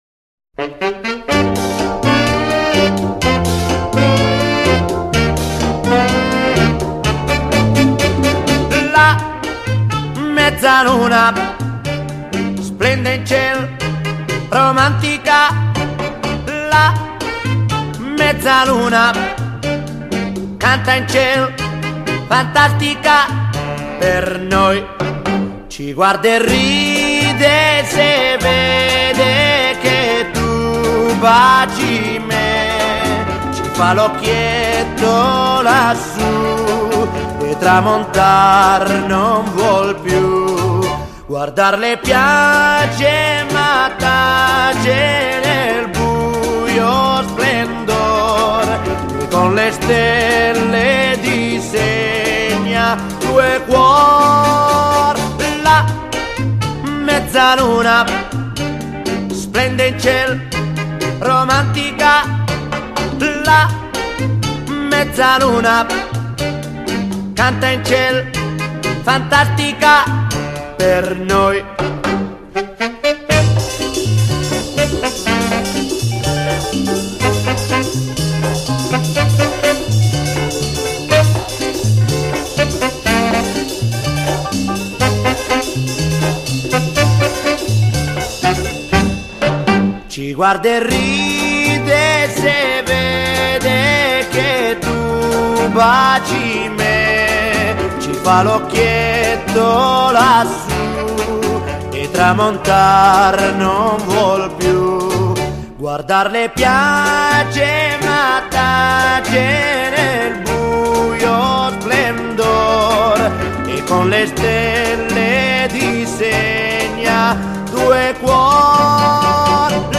Pop, Soul, Easy Listening, Oldies